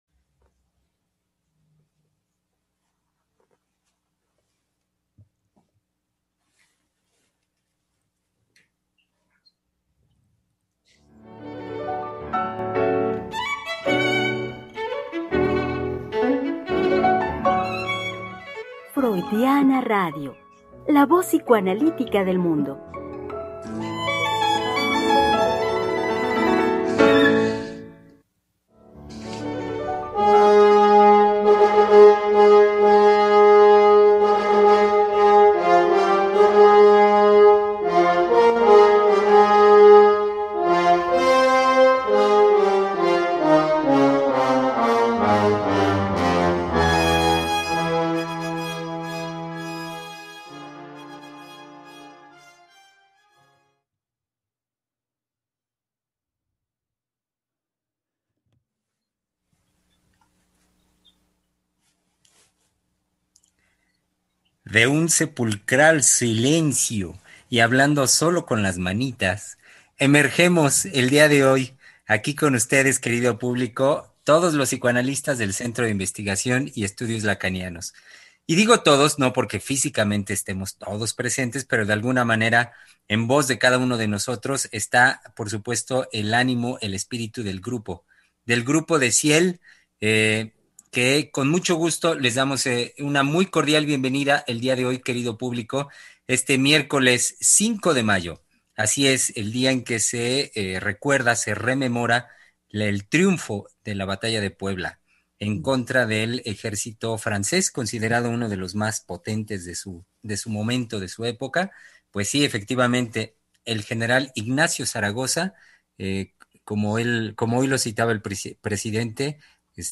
Programa transmitido el 5 de mayo del 2021.